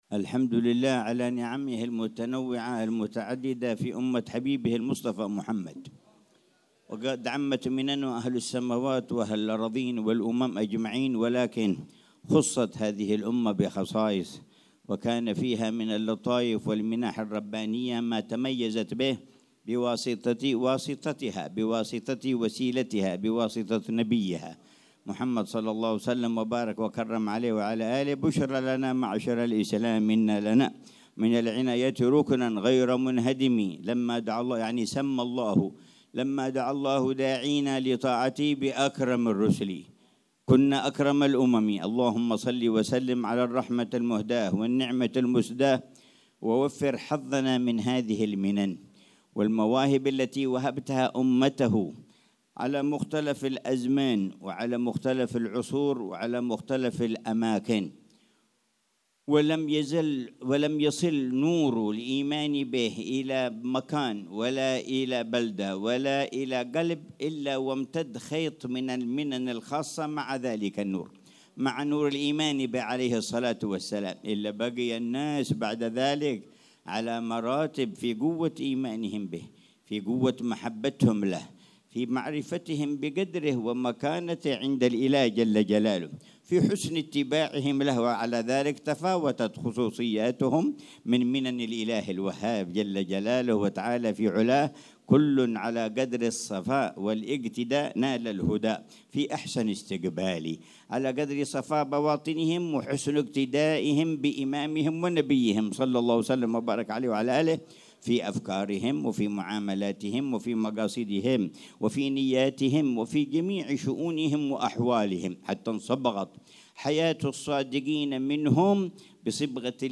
محاضرة العلامة الحبيب عمر بن حفيظ في بيت بازمول بمدينة سيئون، ظهر الخميس 26 ربيع أول 1447هـ بعنوان: